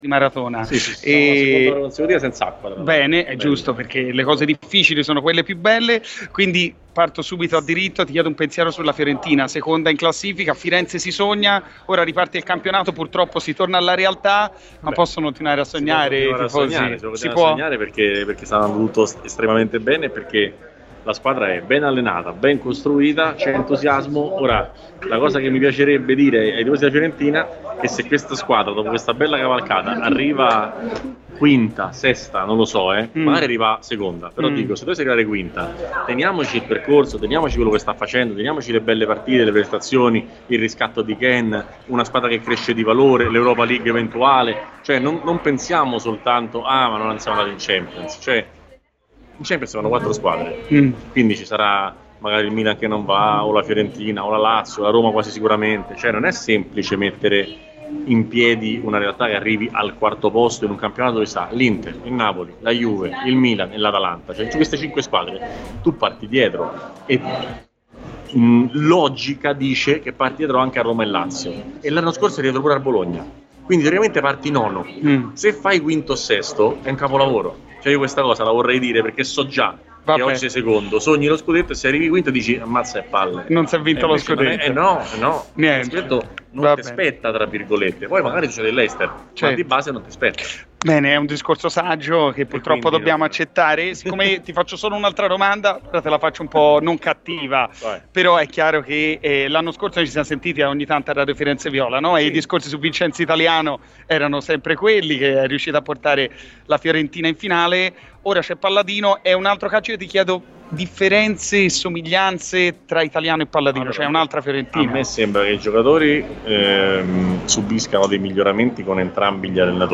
Riccardo Trevisani, giornalista sportivo, è intervenuto in esclusiva a FirenzeViola: dal Social Football Sumiit di Roma: "Firenze può continuare a sognare perché è una squadra ben allenata e ben costruita.